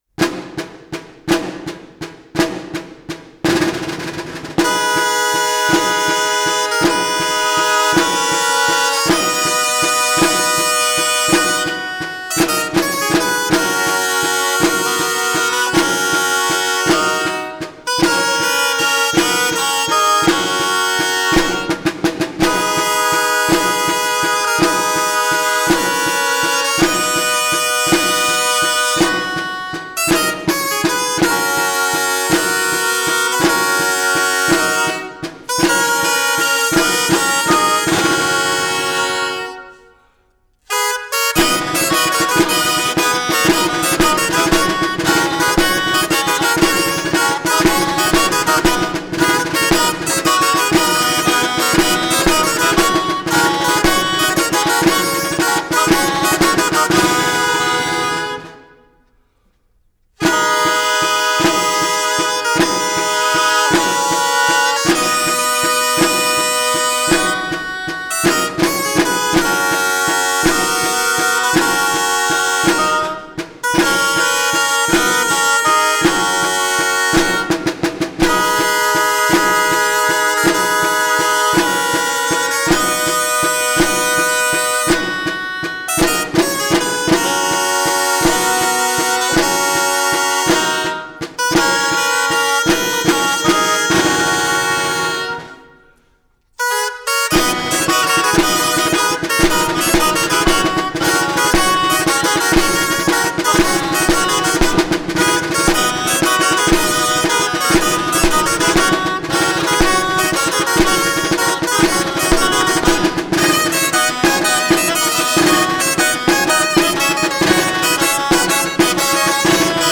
BALL DE LA MULASSA DE TARRAGONA Grallers La Virolla
Santa Tecla Tarragona